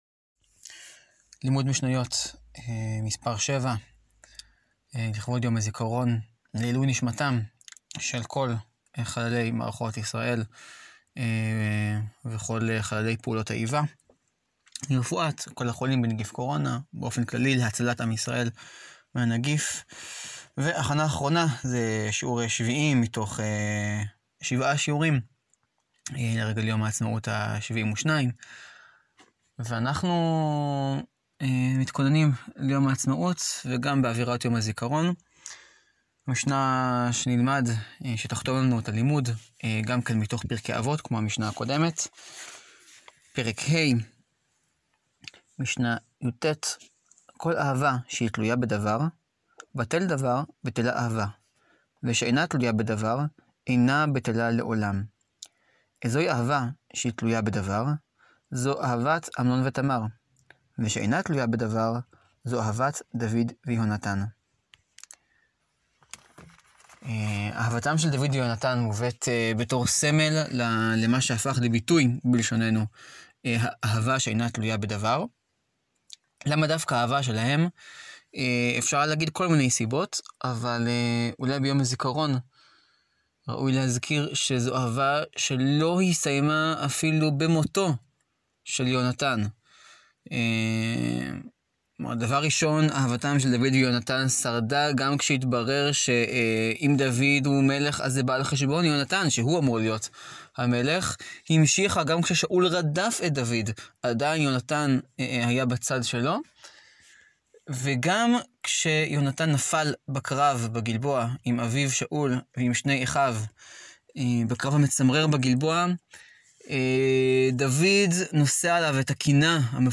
לימוד יומי בשאלה הנשאלת לראשונה מזה 72 שנה: האם יש משמעות לחגיגת עצמאות לבד בבית? שיעורון מספר 7: אהבה שאינה תלויה בדבר לימוד המשניות מוקדש לעילוי נשמת חללי מערכות ישראל ופעולות האיבה, ולרפואת החולים בנגיף קורונה.